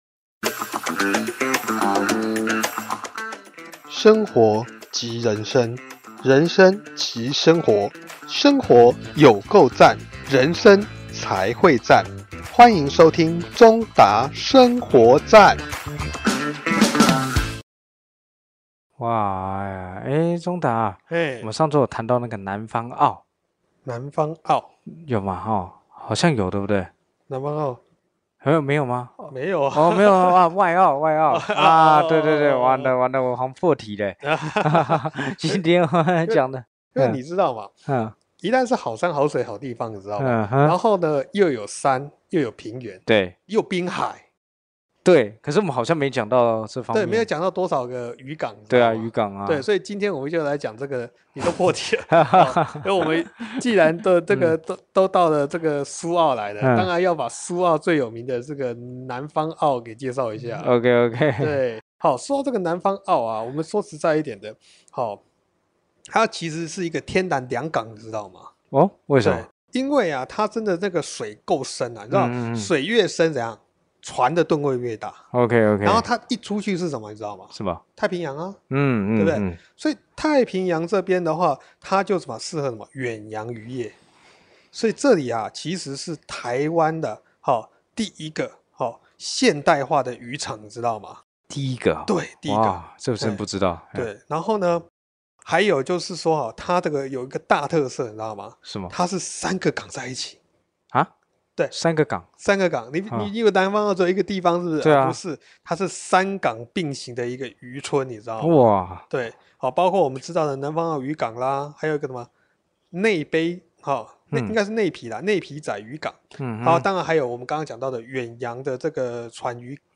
節目裡有生活點滴的分享、各界專業人物的心靈層面探析及人物專訪，比傳統心理節目多加了歷史人物與音樂知識穿插，更為生動有趣，陪伴您度過深夜時光，帶來一週飽滿的智慧與正能量。